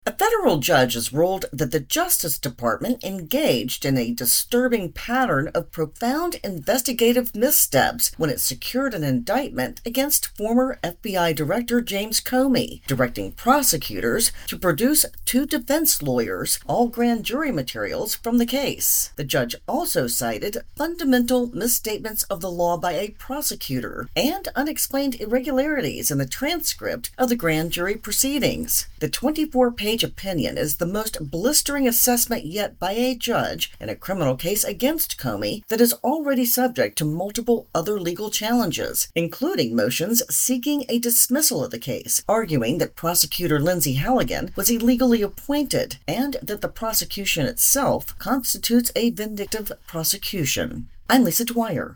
report on a blistering ruling by a judge in the James Comey case.